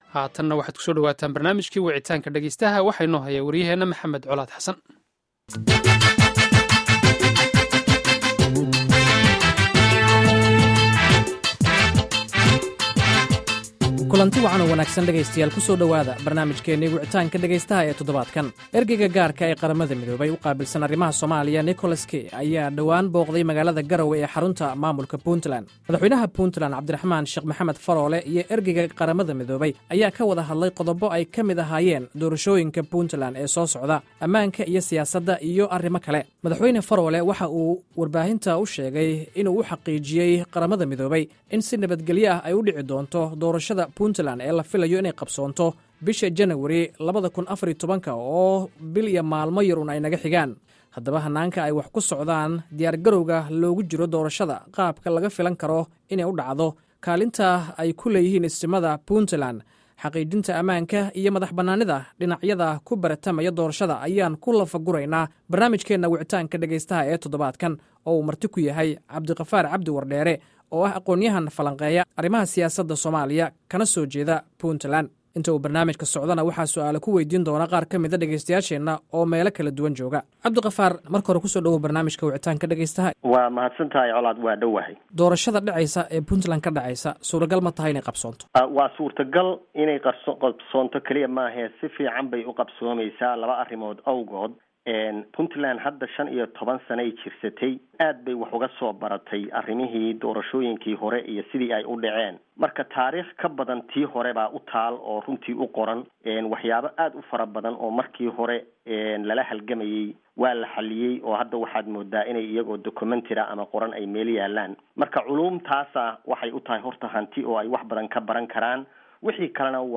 Dhageyso Barnaamijka Wicitaanka Dhageystaha